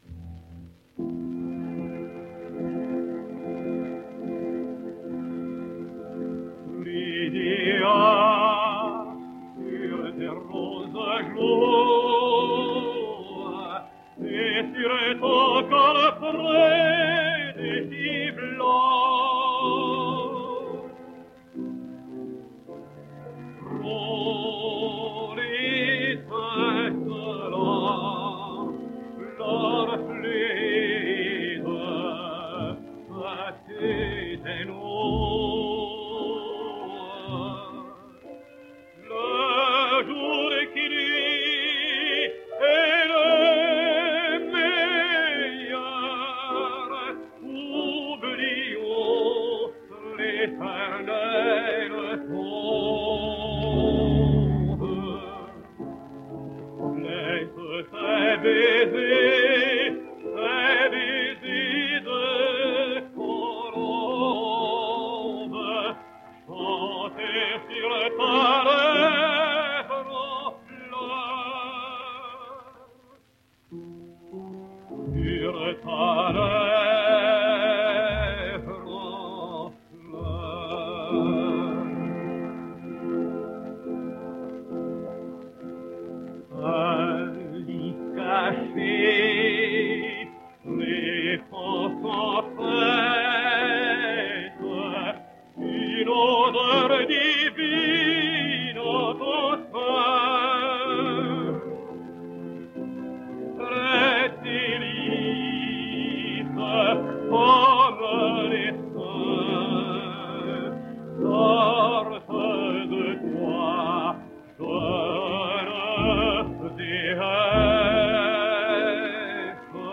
French Tenor.